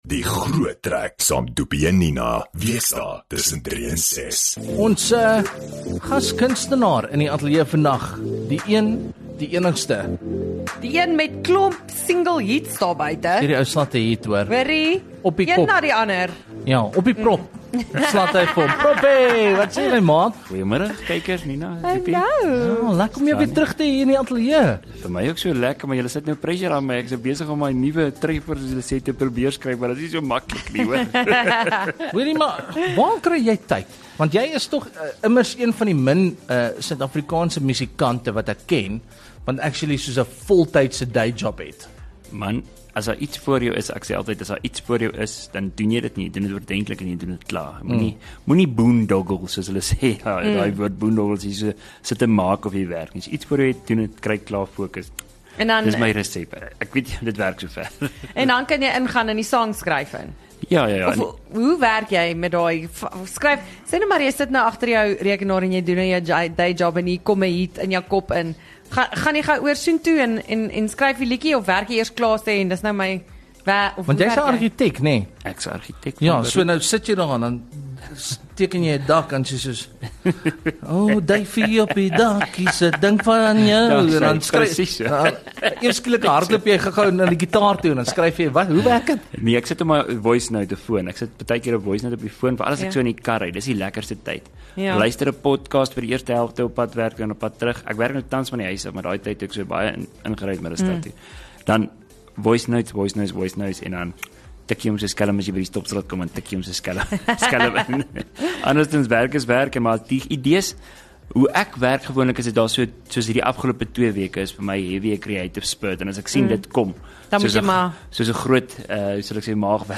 Ons gesels ook bietjie familie lewe en oor sy nuwe musiek video terwyl hy ons vermaak met n paar lekker akoestiese nommertjies.